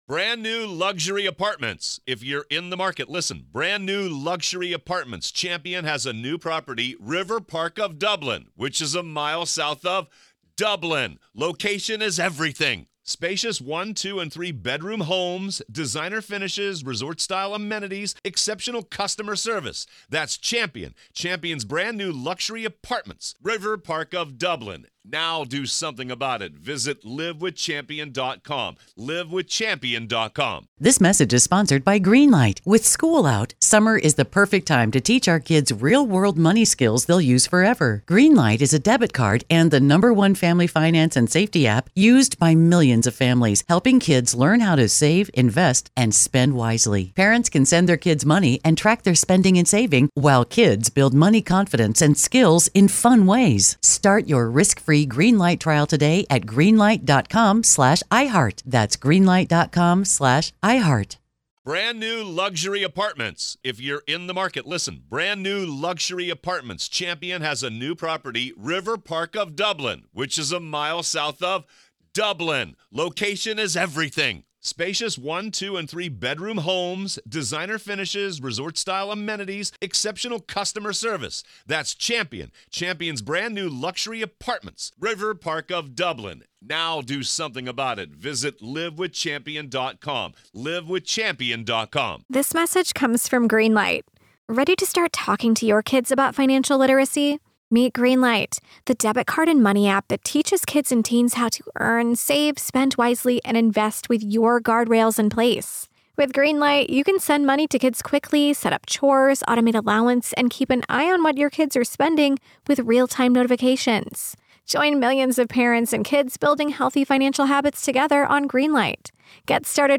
Motions Hearing